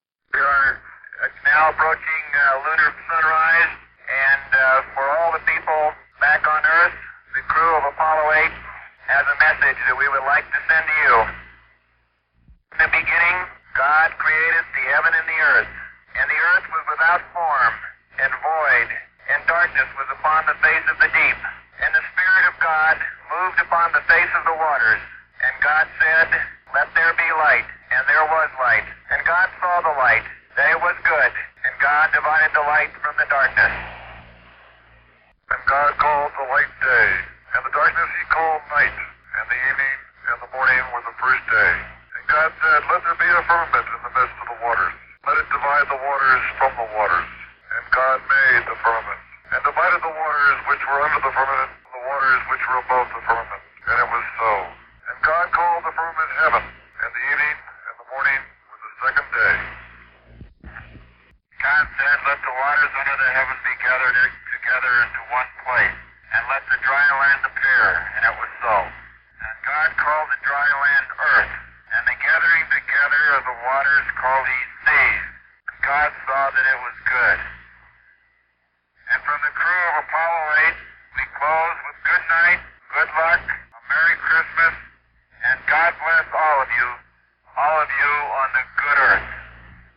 Anders, Lowell and Borman conclude their Christmas Eve broadcast from the Apollo 8 Command Module while orbiting the Moon, December 24th, 2018
Christmas-Greeting.mp3